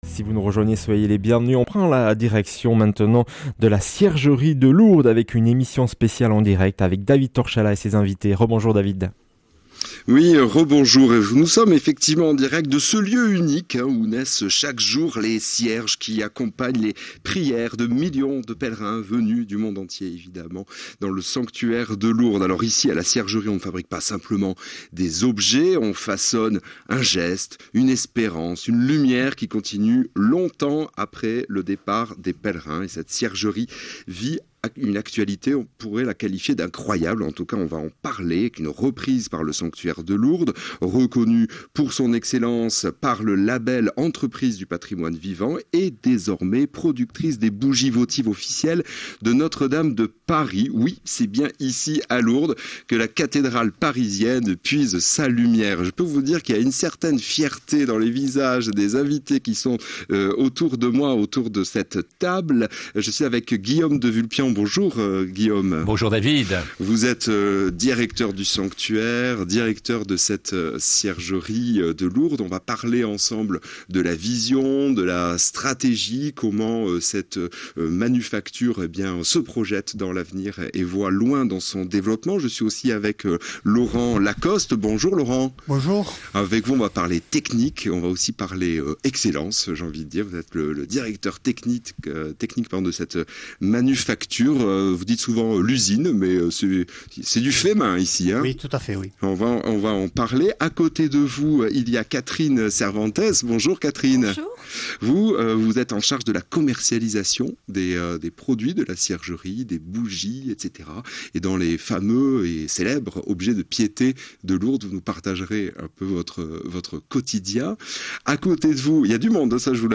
Emission spéciale en direct de la ciergerie de Lourdes.
Accueil \ Emissions \ Information \ Locale \ Interview et reportage \ Emission spéciale en direct de la ciergerie de Lourdes.